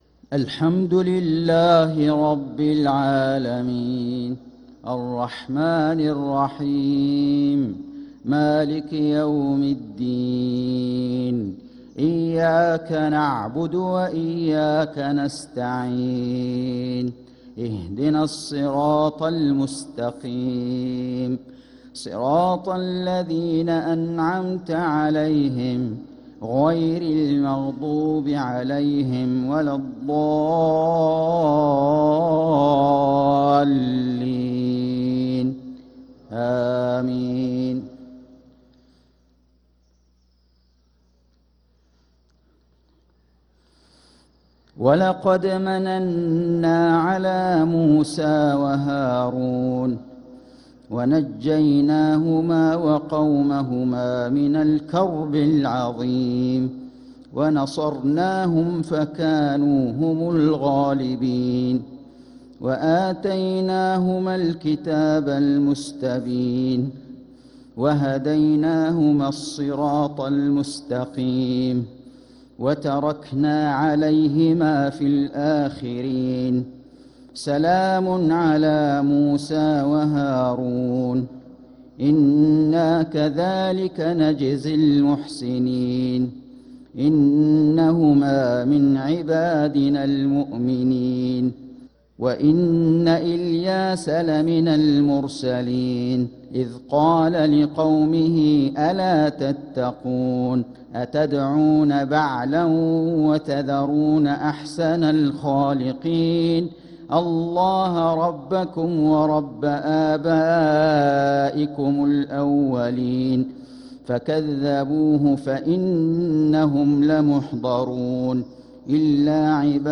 صلاة المغرب للقارئ فيصل غزاوي 24 صفر 1446 هـ
تِلَاوَات الْحَرَمَيْن .